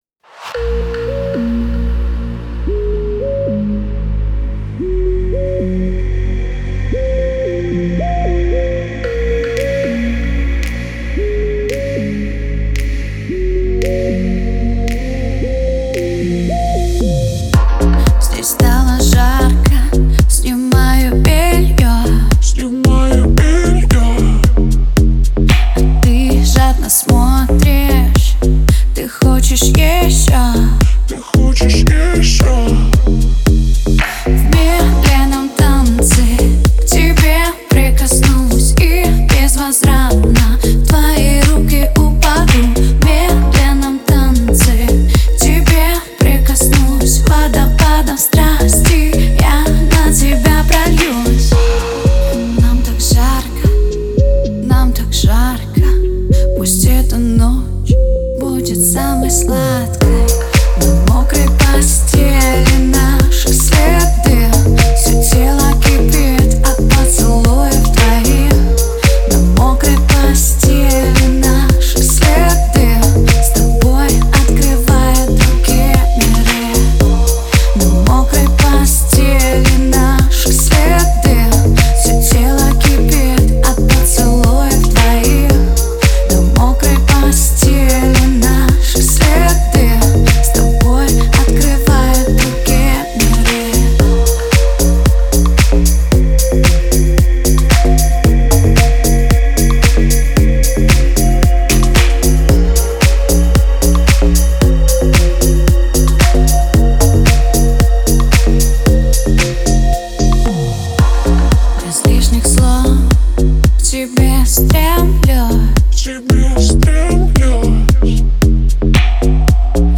Жанр:load / Клубные новинки